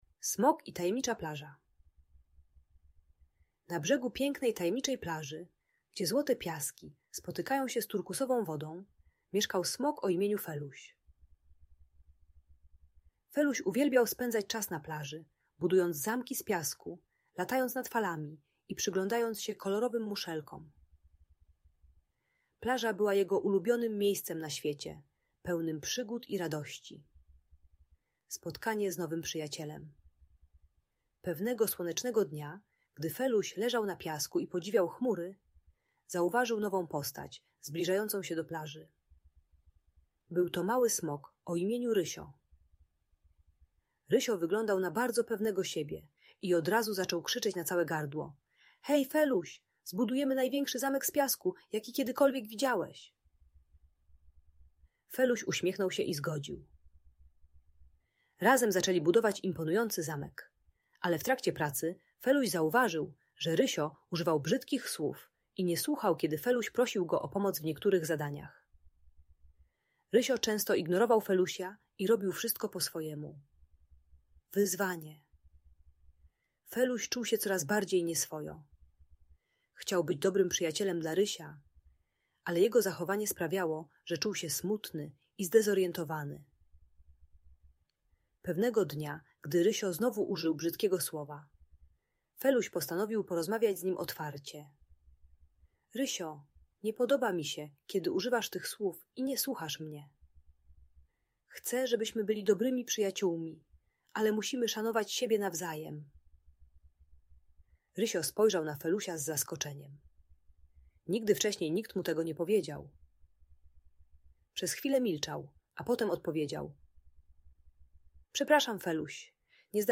Smok i Tajemnicza Plaża - historia przyjaźni i przygód - Audiobajka